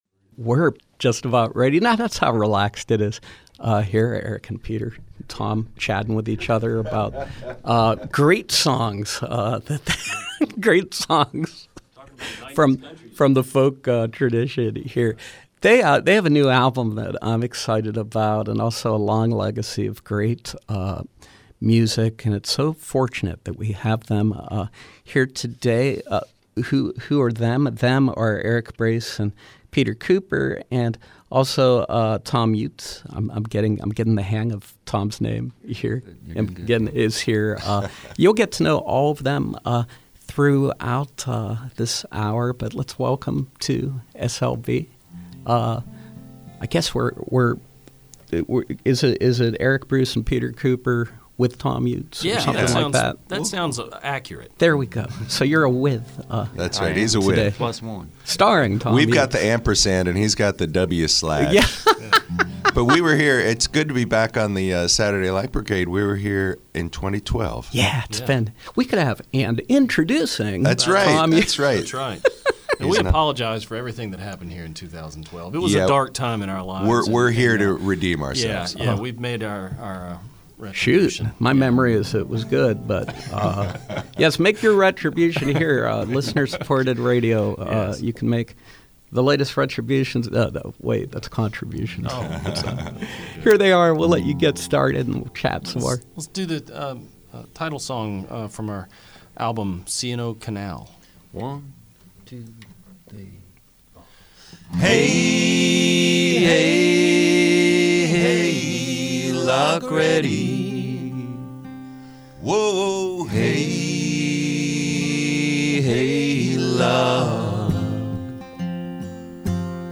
Grammy-nominated acoustic duo